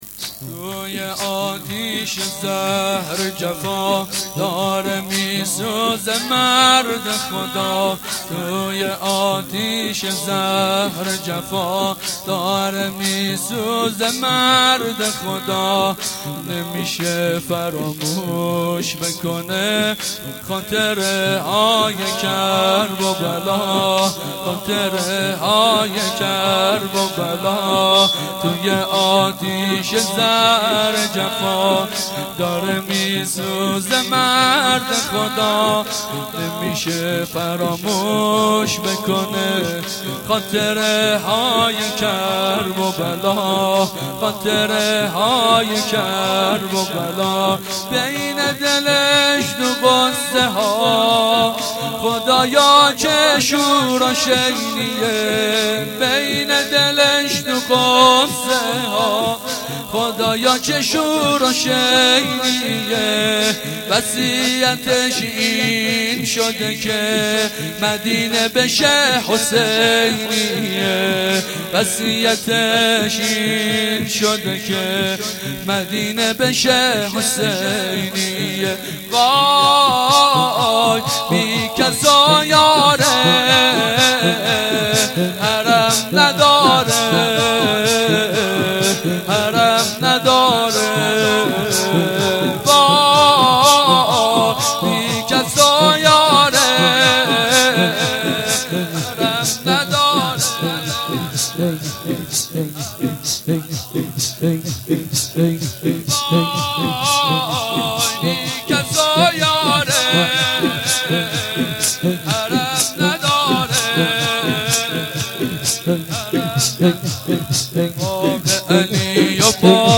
شور
shoor3-Rozatol-Abbas.shahadat-emam-Bagher.mp3